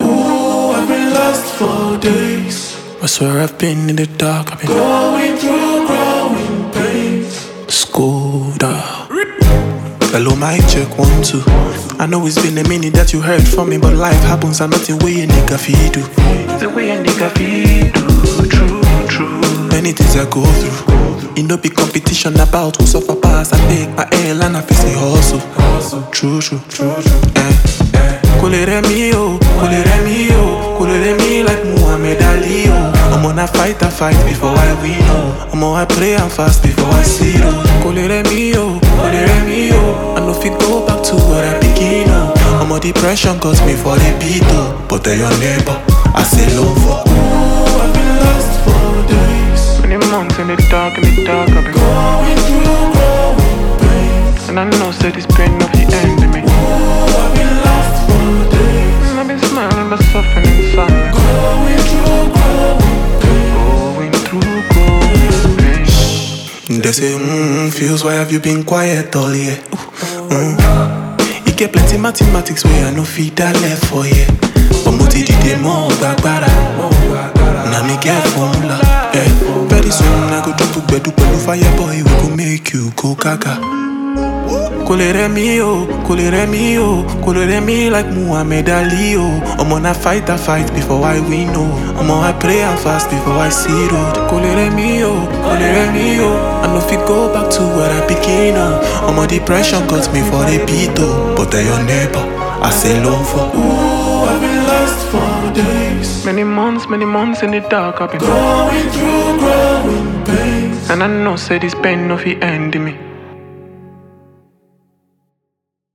With his signature mix of Afro-fusion and heartfelt lyrics